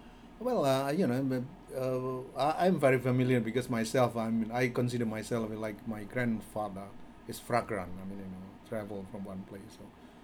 Corpus of Misunderstandings from the Asian Corpus of English
S1 = Indonesian male S2 = Malaysian female Context: S1 is discussing travelling around.
Intended Words: vagrant Heard as: fragrant
The word begins with [f] rather than [v], there is an [r] after the initial consonant, and the vowel in the two syllables is similar.